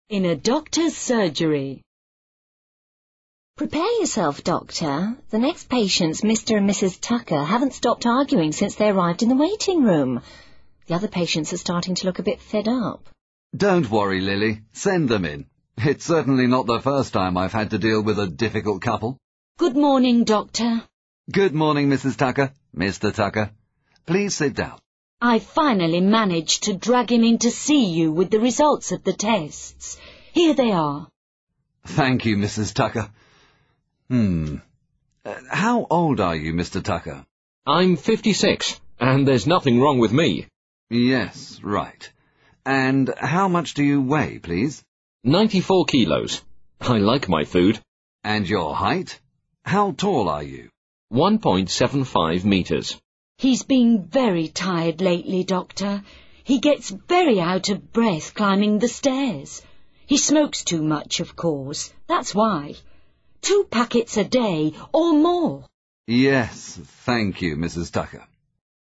Listen to part 1 of the conversation again and complete the activity on the right.